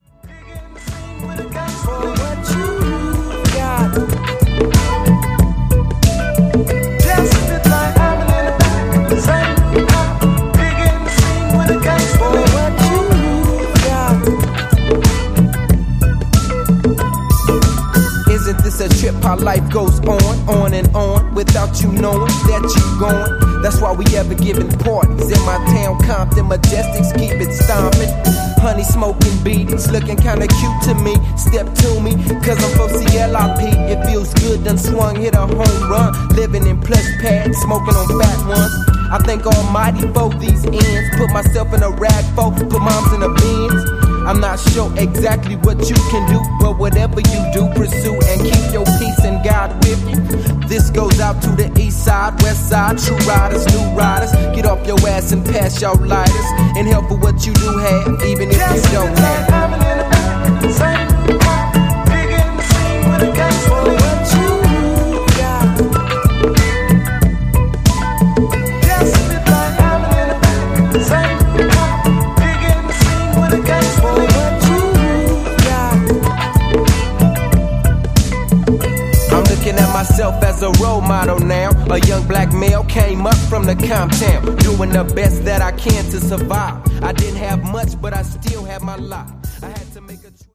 ジャンル(スタイル) RAP / HIP HOP